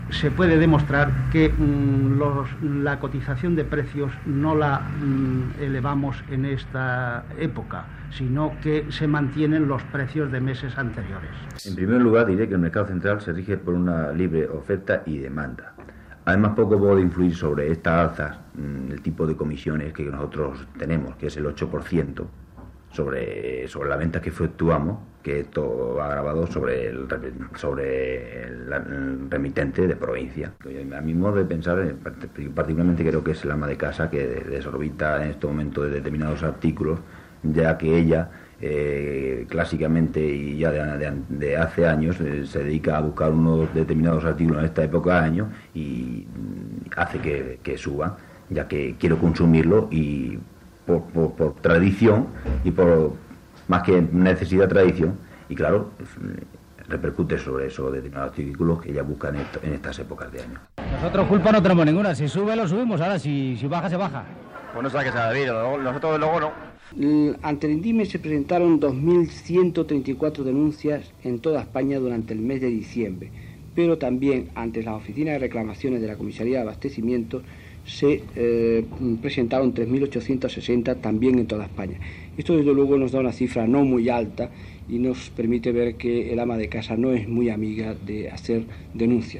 Reportatde sobre l'increment de preus de l'alimentació durant els dies de Nadal
Informatiu